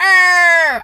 bird_vulture_croak_05.wav